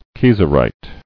[kie·ser·ite]